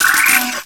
pokeemerald / sound / direct_sound_samples / cries / ferroseed.aif
-Replaced the Gen. 1 to 3 cries with BW2 rips.